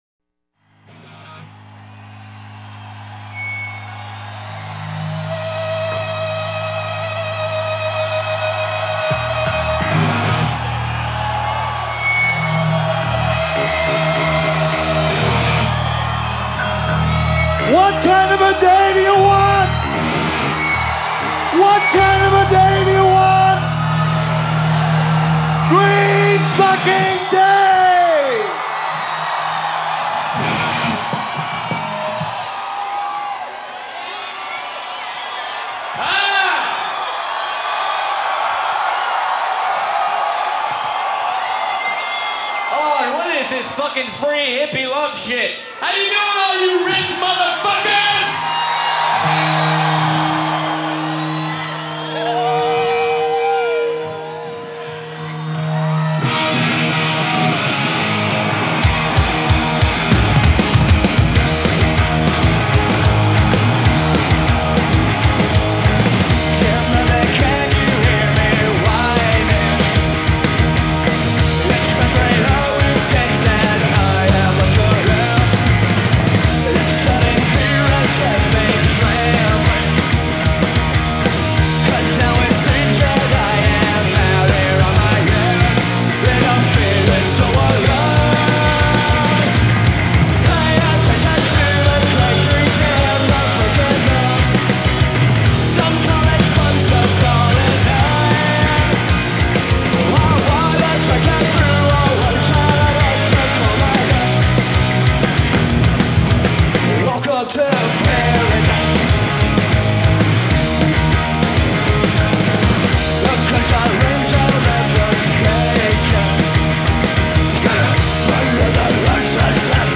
Live at Woodstock 1994